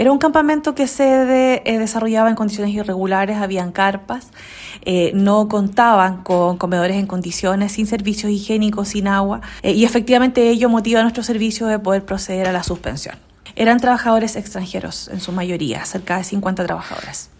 La directora regional del Trabajo, Carolina Ojeda, relató que se encontraban alojados en carpas improvisadas, sin acceso a agua potable suficiente y sin baños.